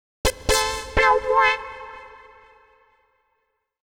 XTRA051_VOCAL_125_A_SC3.wav